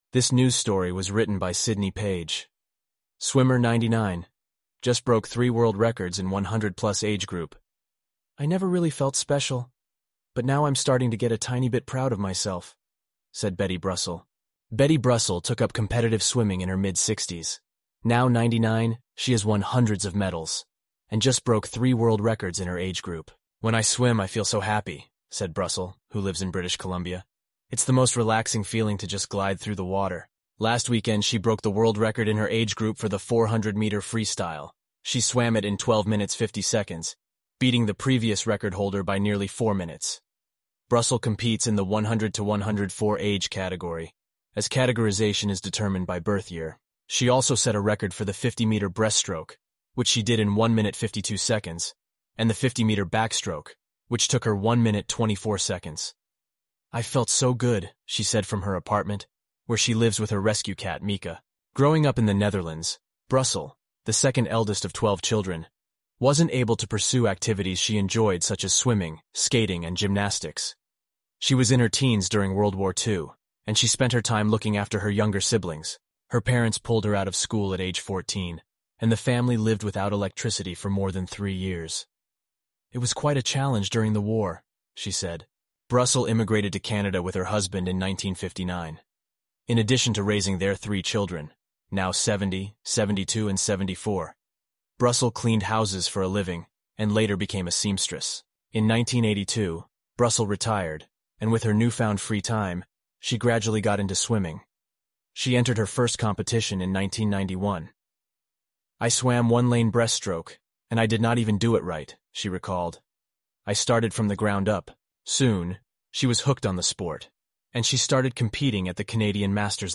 eleven-labs_en-US_Josh_standard_audio.mp3